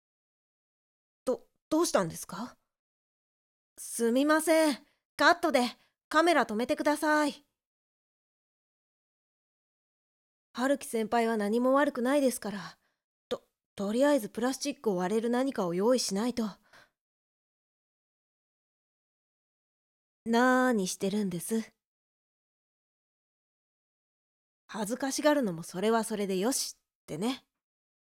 番外編声劇